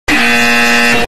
Extremely Loud Incorrect Buzzer